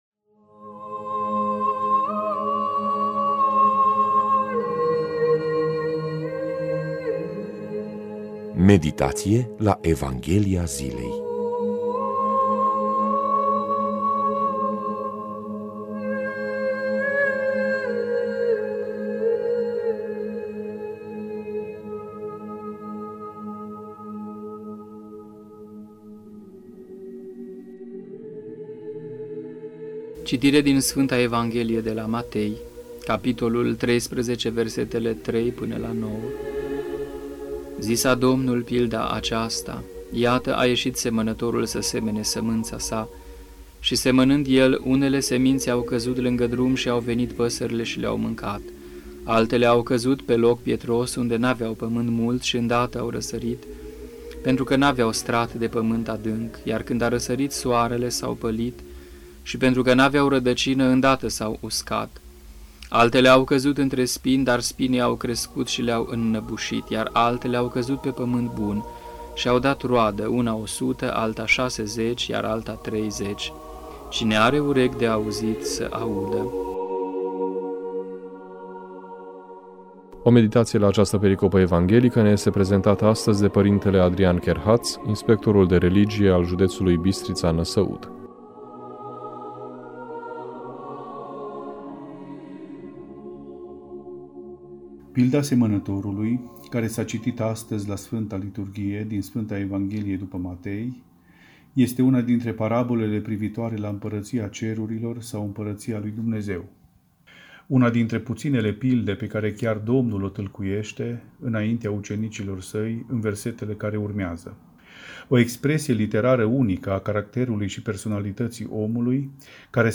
Meditație la Evanghelia zilei